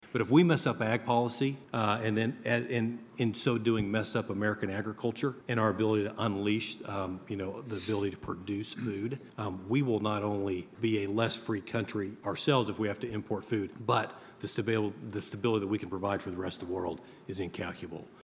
A panel discussion, hosted by Farm Journal Foundation and Kansas State University Tuesday at the Stanley Stout Center, focused on how agricultural innovations can mitigate global hunger and malnutrition.